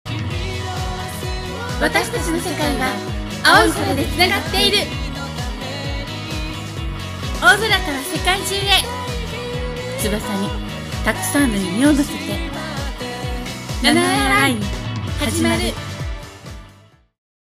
ナレーション】翼に、夢を。